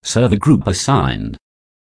TeaWeb / shared / audio / speech / group.server.assigned.self.wav